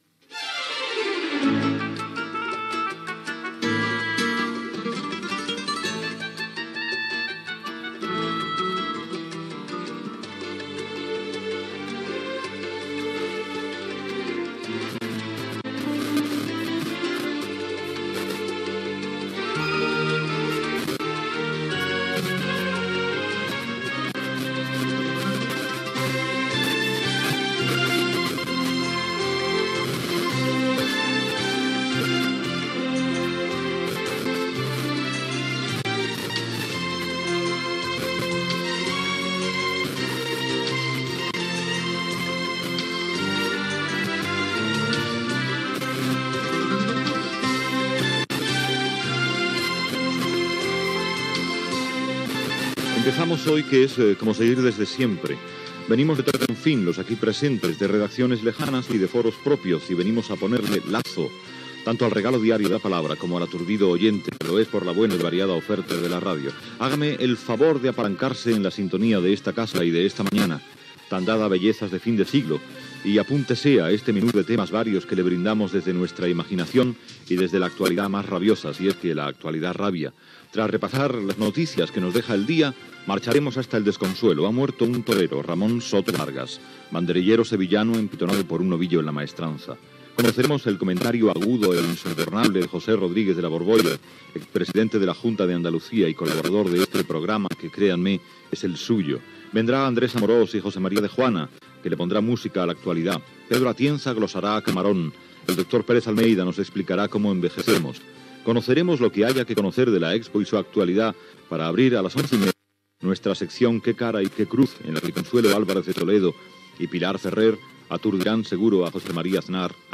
Identificació del programa,sintonia i presentació del primer programa, sumari de continguts, tema musical, noms propis del dia
Info-entreteniment
Herrera, Carlos